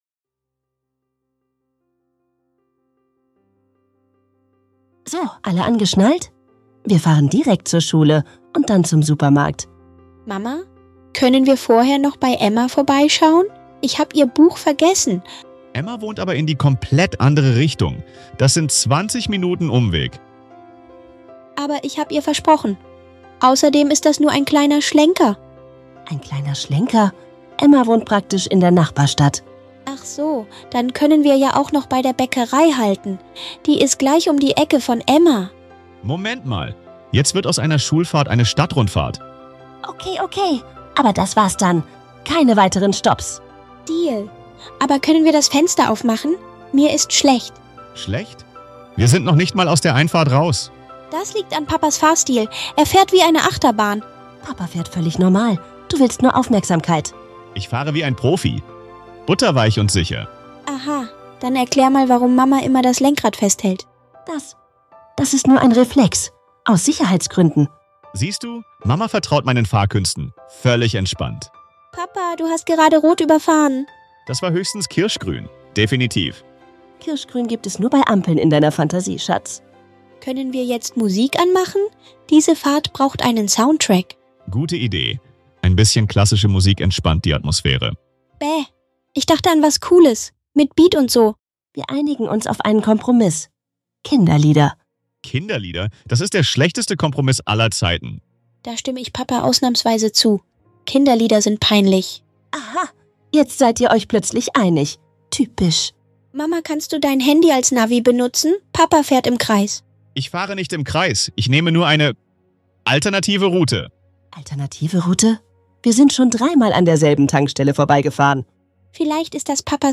Abenteuer des Tages! In diesem super lustigen Dialog erleben wir